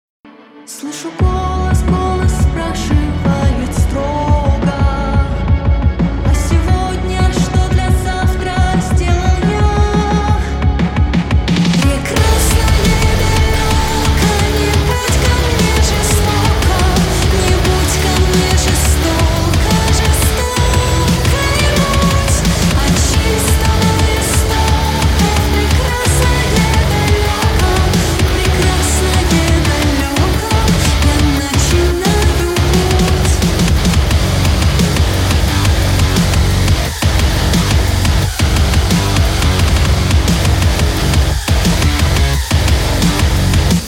piosenka rosyjska